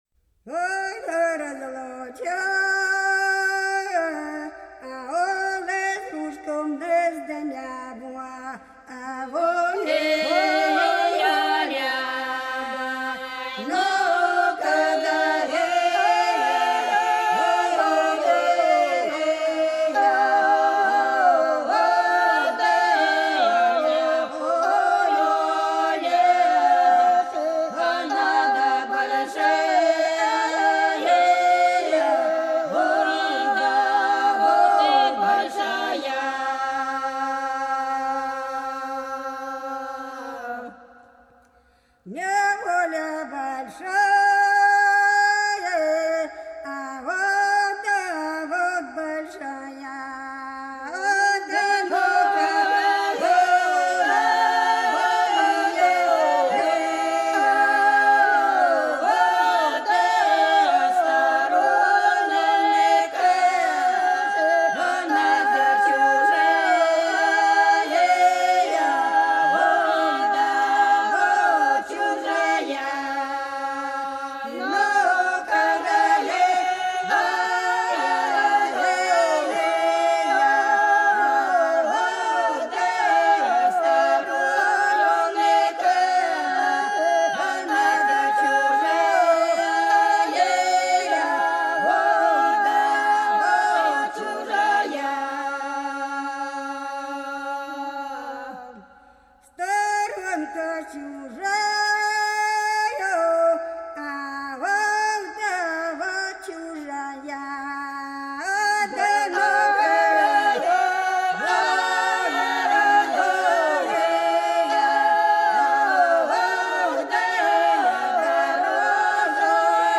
Долина была широкая (Поют народные исполнители села Нижняя Покровка Белгородской области) Разлучила нас с дружком неволя - протяжная